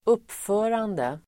Uttal: [²'up:fö:rande]